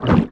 Sfx_creature_penguin_swim_03.ogg